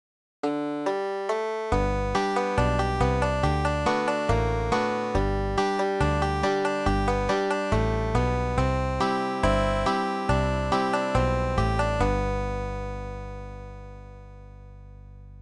one section on lower neck - G tuning)